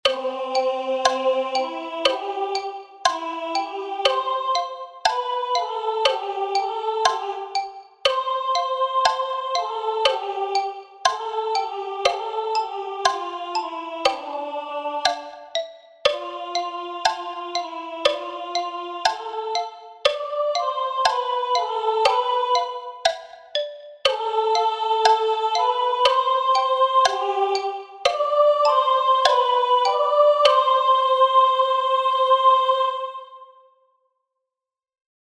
Exercise to reand and sing with sound file